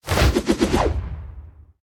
archer_skill_spinkick_swing_a.ogg